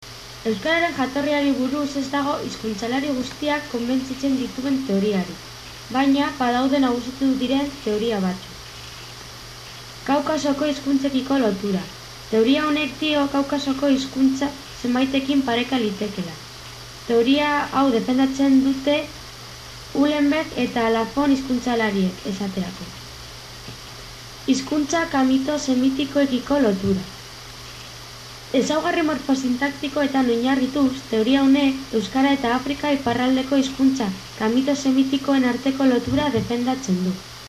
recording in a mystery language. Can you guess or do you know which language it is?
It has somehow a latin sound (I know Basque is an isolated language after all)… I thought it is more like something from South America … like Quechua.
7. i’ve always been struck that Basque seems to have Spanish prosody- and in this case the speaker rerally sounds Spanish.